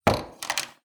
st_cupboardopen.wav